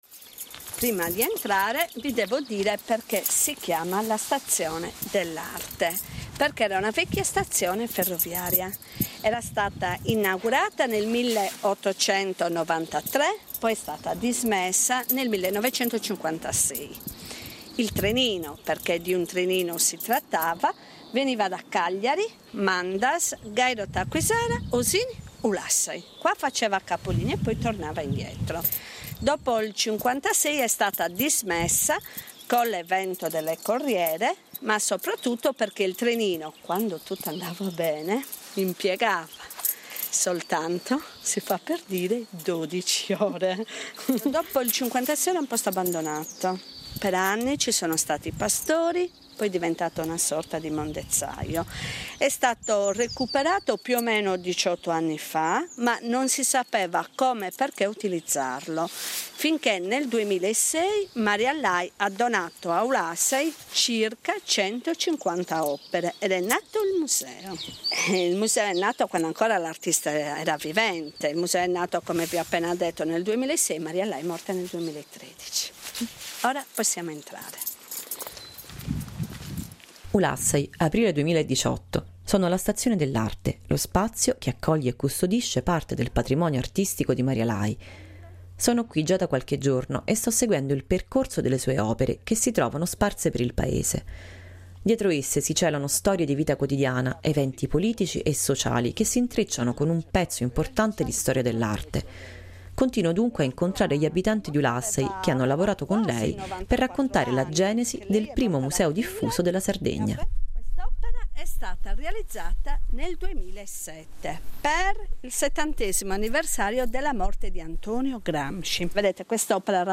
Oggi il museo diffuso di Ulassai è ancora una realtà poco conosciuta e la sua storia è tutta da raccontare. Seguendo un itinerario immaginario creato dalle voci degli ulassesi le opere dell’artista prendono vita e raccontano la storia della loro nascita.